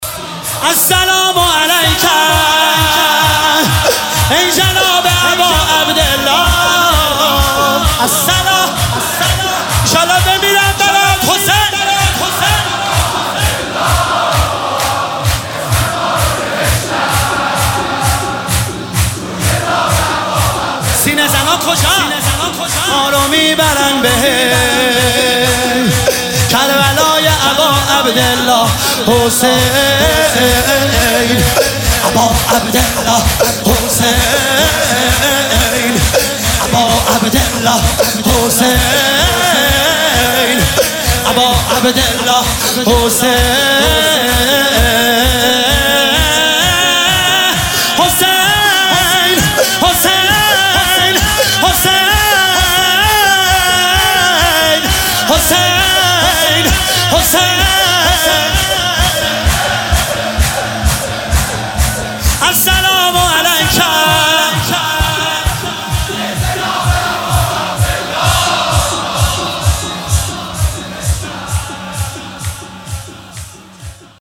شهادت امام جواد (ع) 1404